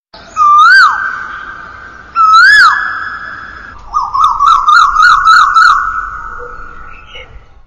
Catégorie: Animaux